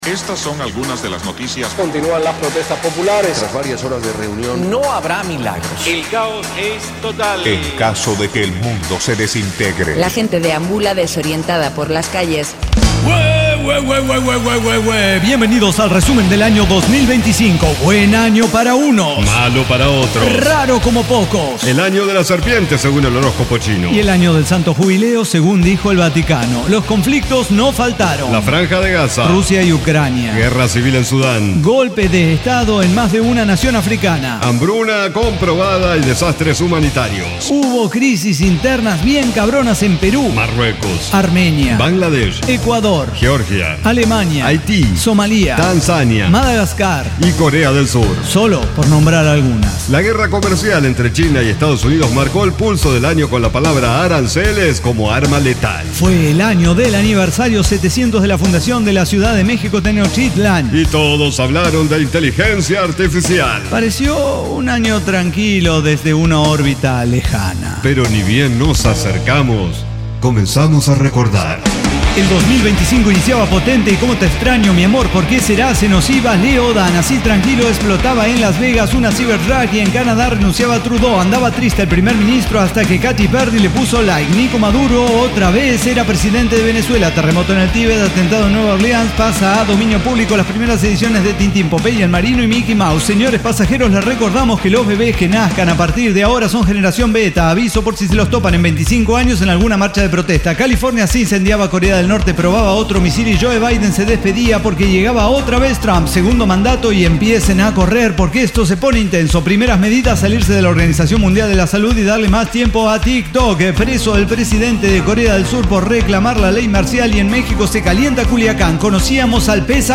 Diseño, guionado, música, edición y voces son de nuestra completa intervención humana